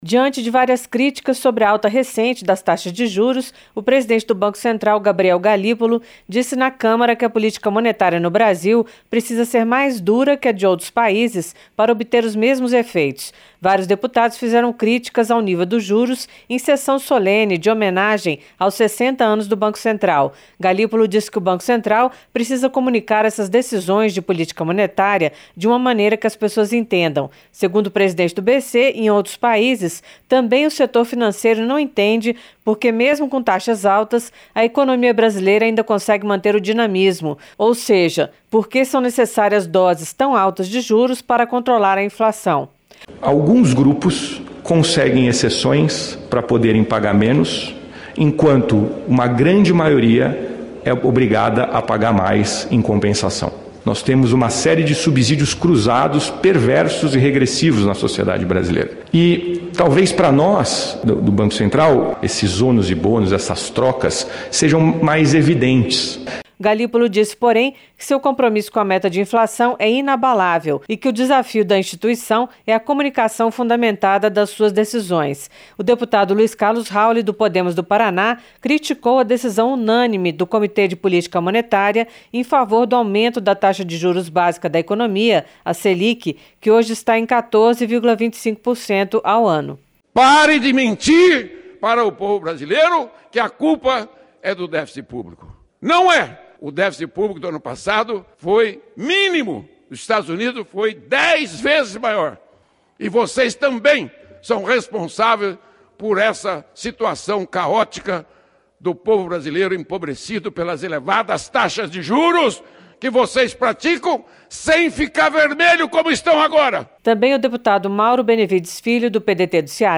Durante sessão solene pelos 60 anos do BC, deputados criticam juros altos